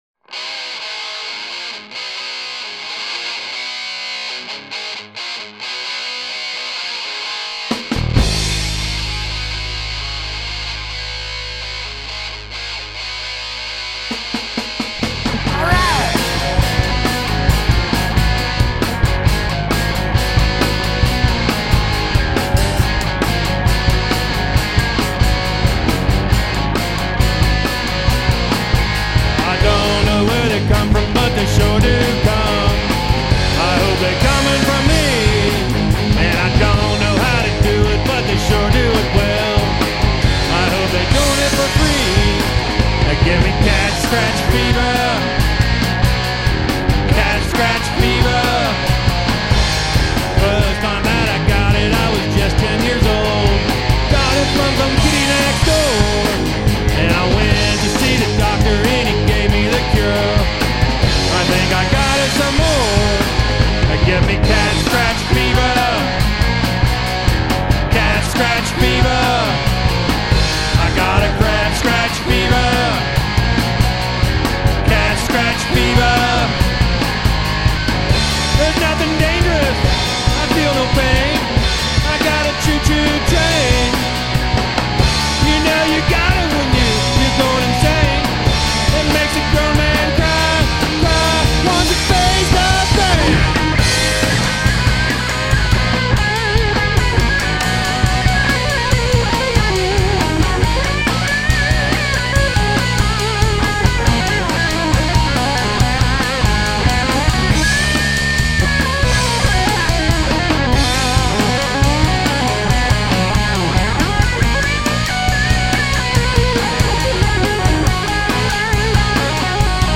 Drums and Backing Vocals
Guitar Intro and Rythm Guitar